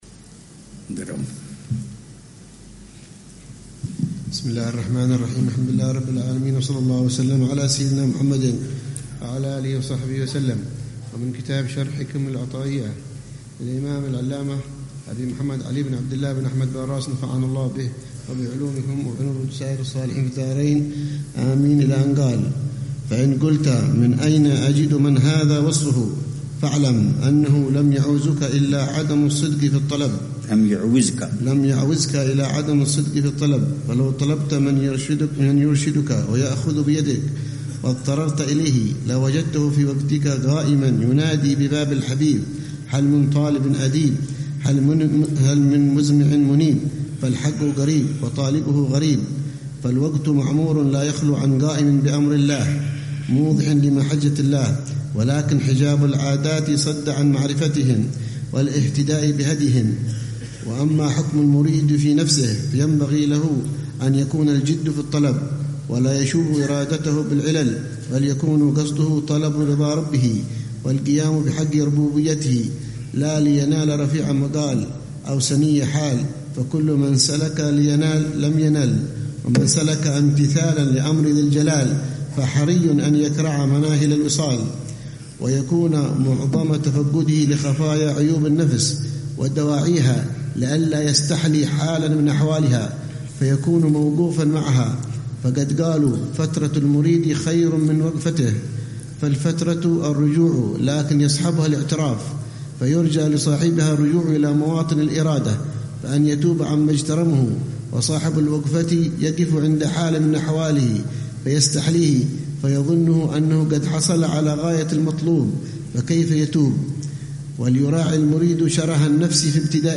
شرح العلامة الحبيب عمر بن محمد بن حفيظ لكتاب شرح الحِكم العطائية للشيخ علي بن عبدالله با راس رحمه الله تعالى، ضمن فعاليات الدورة التعليمية الح